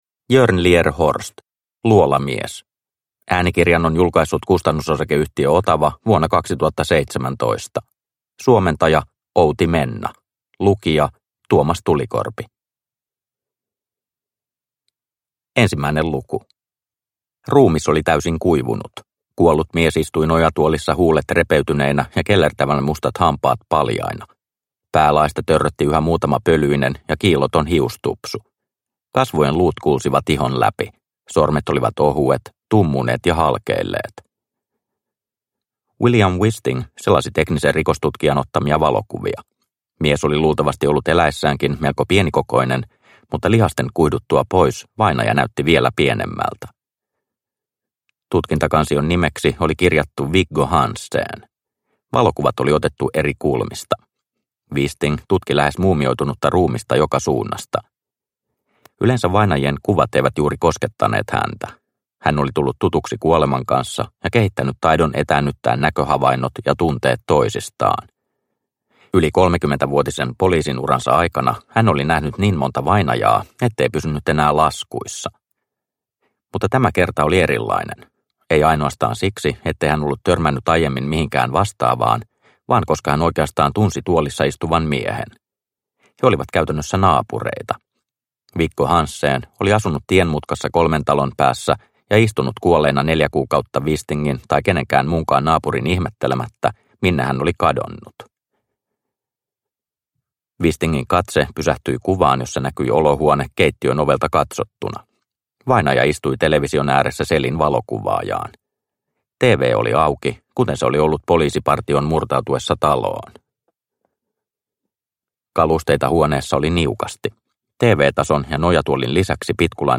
Luolamies – Ljudbok – Laddas ner